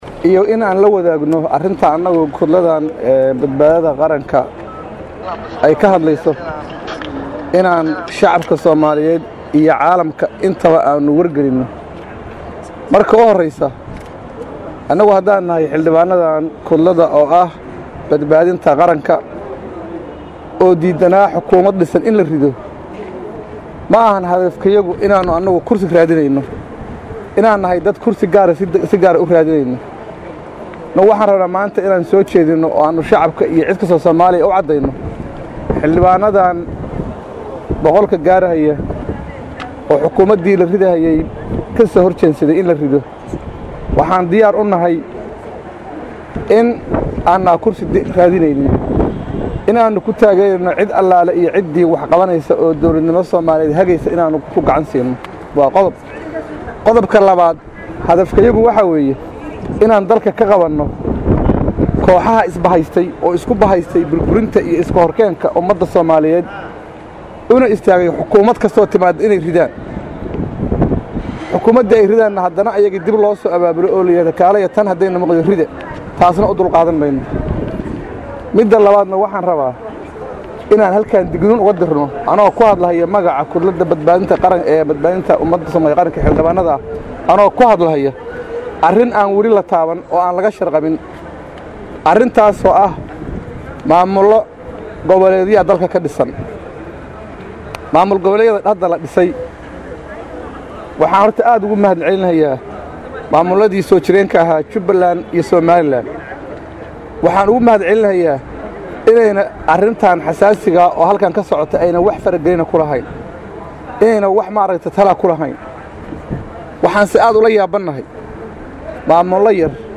Xildhibaan Cabdi Barre Yuusuf oo shirka ka dib la hadlay warbaahinta ayaa sheegay in madaxweynayaasha maamulada Jubba iyo Koonfur galbeed Soomaaliya fara gelin ku hayaan soo xulista xubnaha wasiirada cusub.